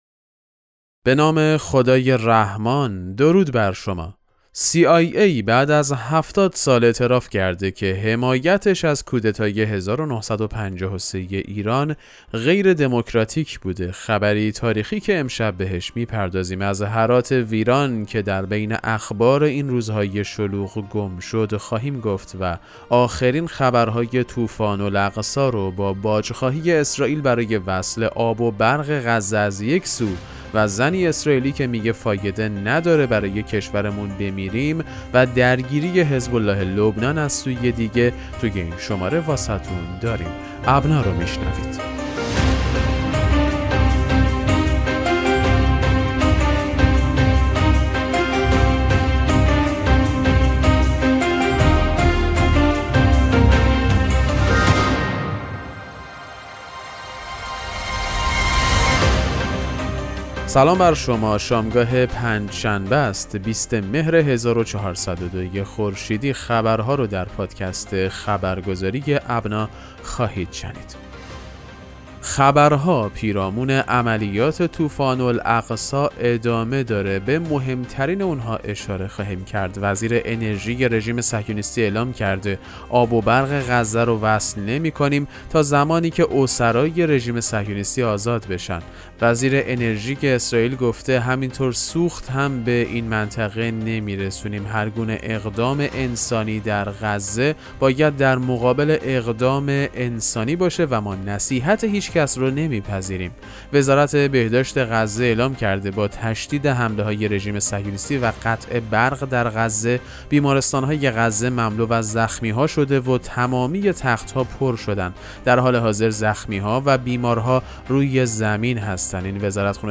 پادکست مهم‌ترین اخبار ابنا فارسی ــ 20 مهر 1402